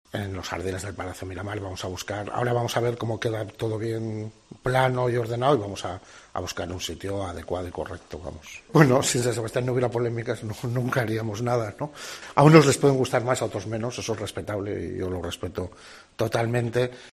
Ernesto Gasco, edil de turismo.